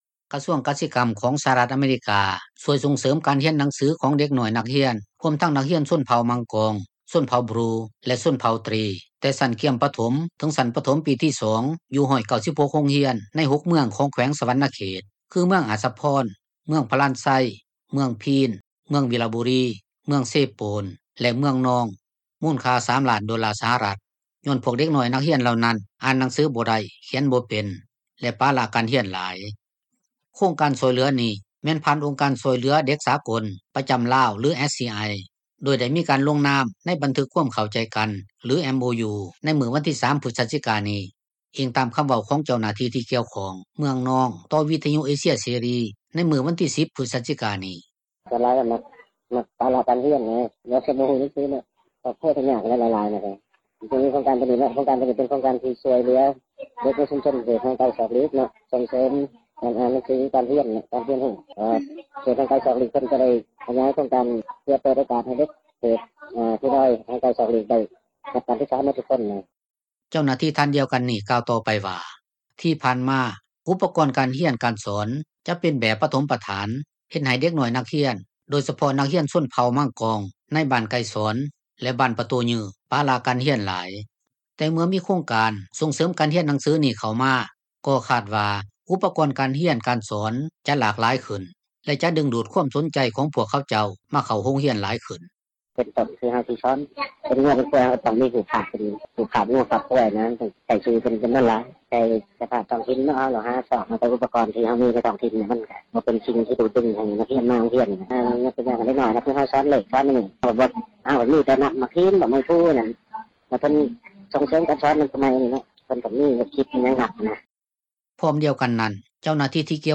ດັ່ງເຈົ້າໜ້າທີ່ ທີ່ກ່ຽວຂ້ອງ ຢູ່ເມືອງເຊໂປນ ທ່ານນຶ່ງກ່າວວ່າ: